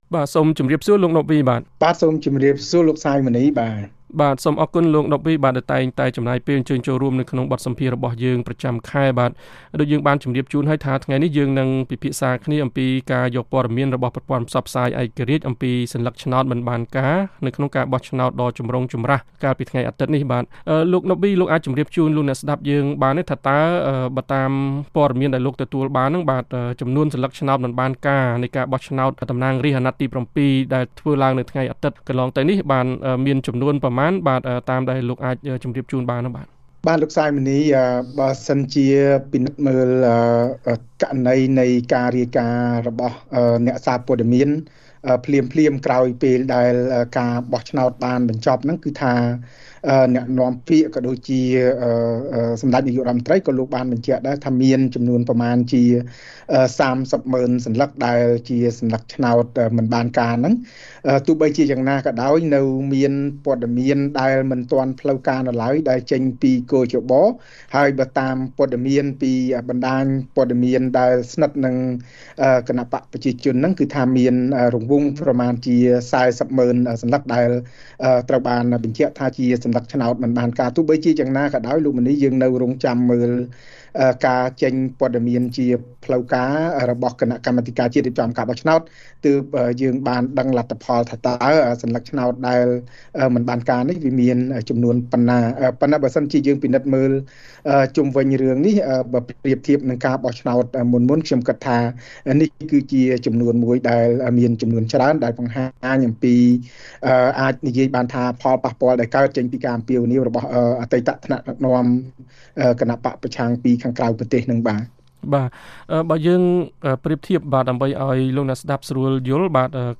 បទសម្ភាសន៍៖ គ.ជ.ប គួរតែពិនិត្យពិច័យឱ្យបានល្អិតល្អន់ពីមូលហេតុនៃសន្លឹកឆ្នោតមិនបានការ